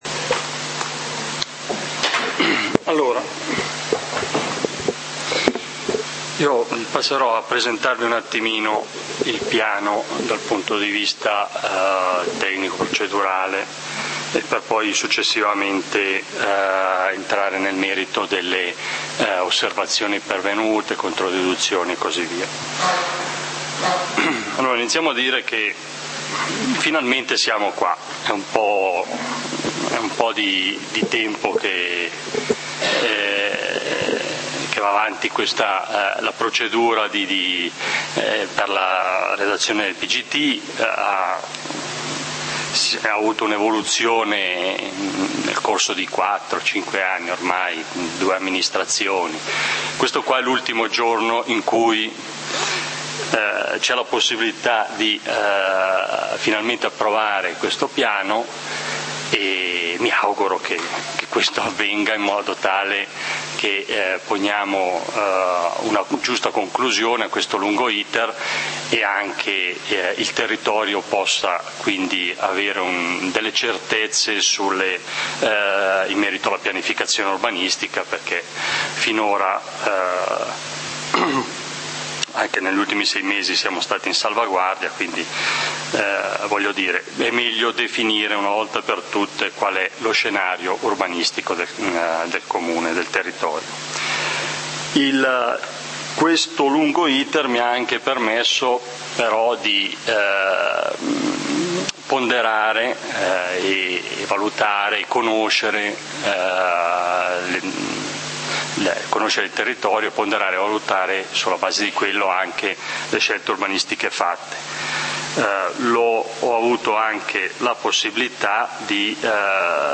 Consiglio comunale di Valdidentro del 30 Giugno 2014